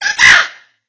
bird_wake_up.wav